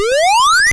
その他の効果音 試聴ダウンロード ｜ seadenden 8bit freeBGM